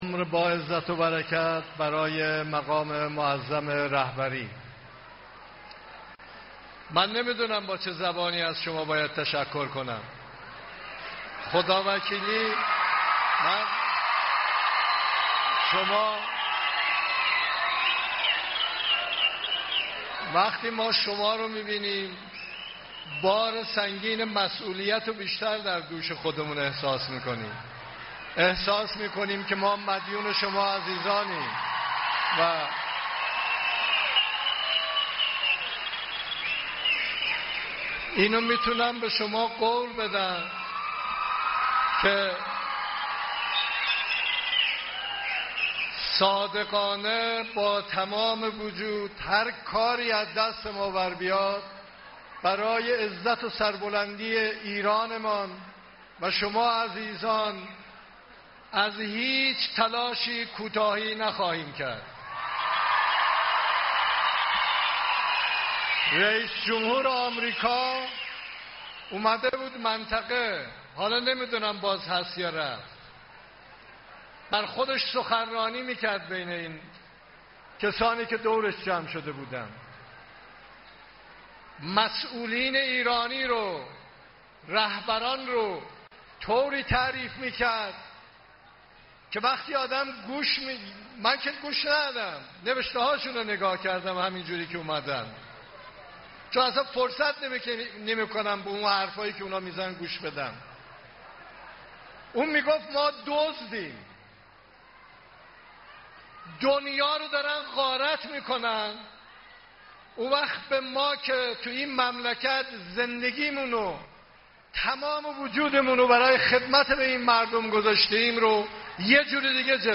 سخنان رئیس‌جمهور در جمع مردم کرمانشاه
تهران- ایرنا- رئیس‌جمهور در جمع مردم کرمانشاه گفت: با وحدت و انسجام و همدلی و با درایت و رهبری رهبر معظم انقلاب همه بحران‌ها را پشت سر خواهیم گذاشت و سربلند بیرون خواهیم آمد.